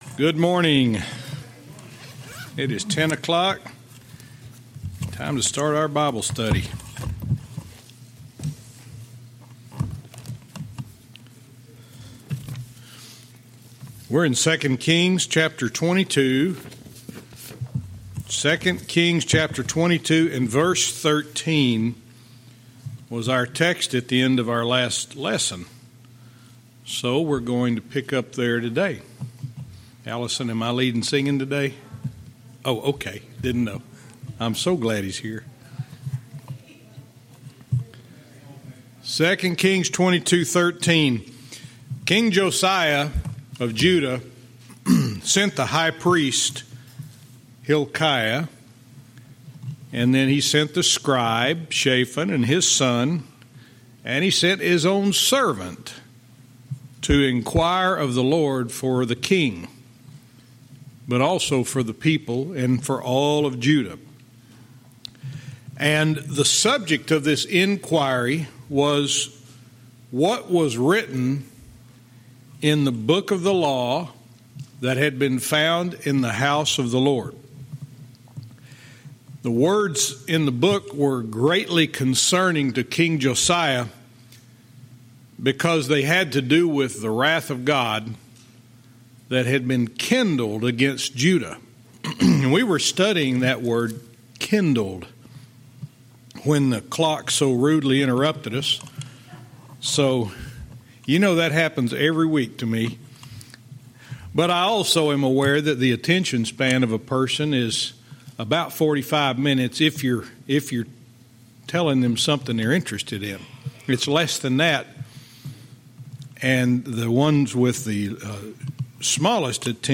Verse by verse teaching - 2 Kings 22:13-16